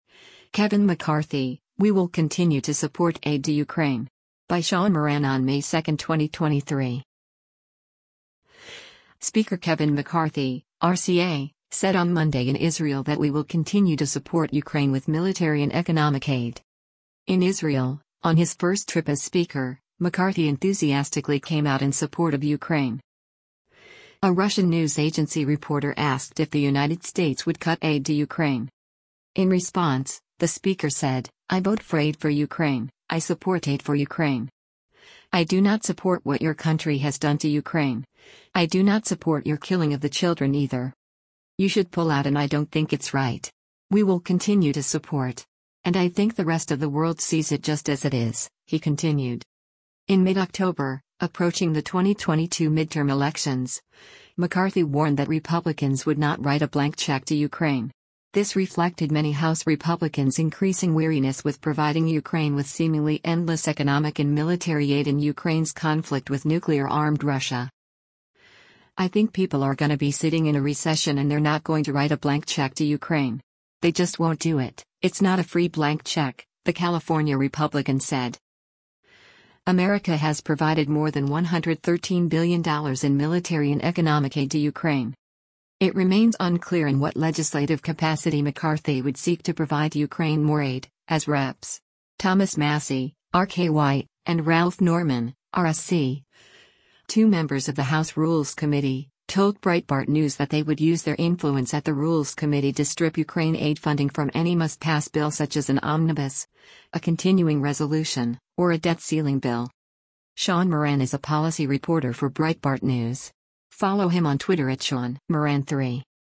Speaker Kevin McCarthy (R-CA)  said on Monday in Israel that “we will continue to support” Ukraine with military and economic aid.
A Russian news agency reporter asked if the United States would cut aid to Ukraine.